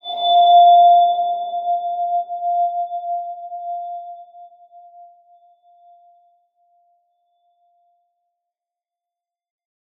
X_BasicBells-F3-pp.wav